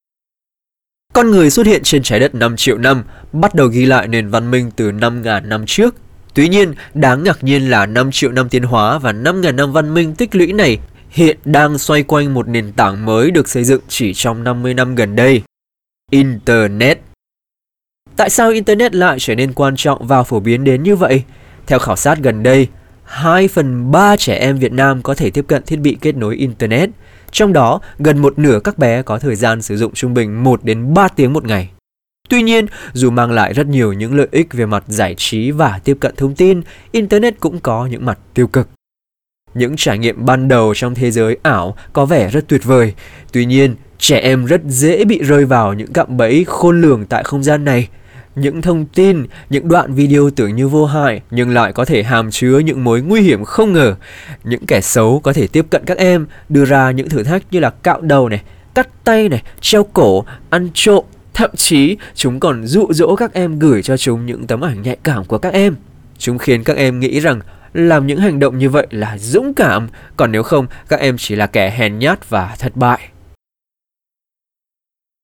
• 8Vietnamese Male No.3
Marketing ads